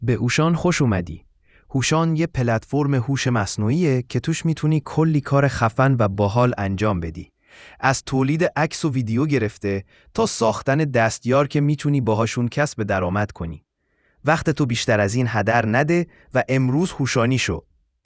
openai-fm-onyx-audio.wav